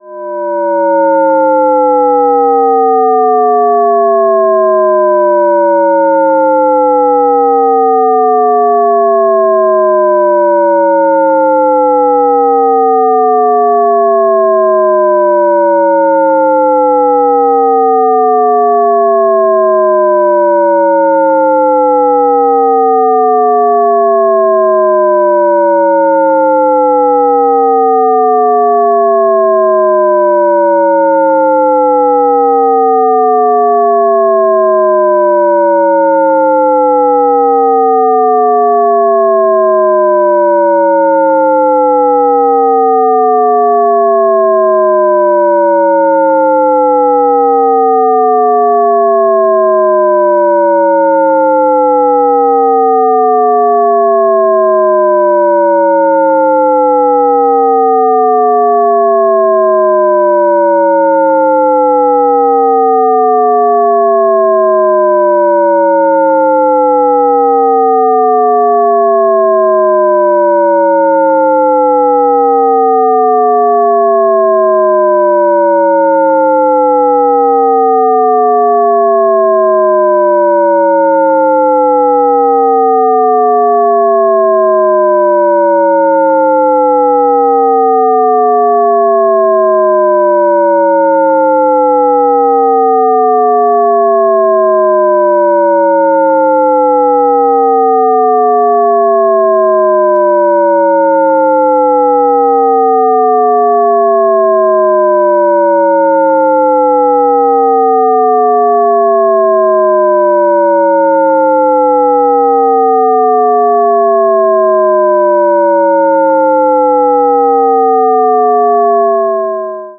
decreasing glissando.
shepard_risset_glissando_downward.ogg